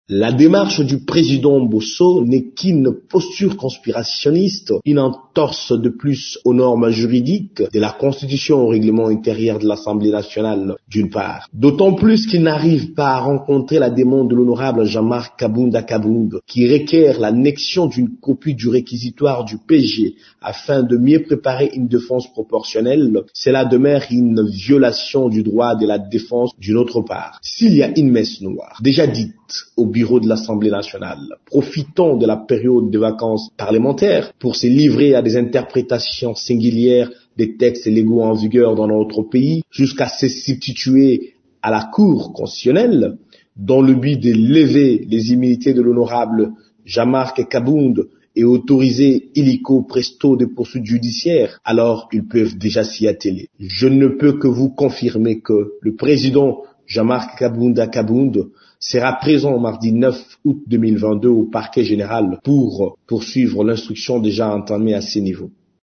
Dans une interview exclusive à Radio Okapi ce matin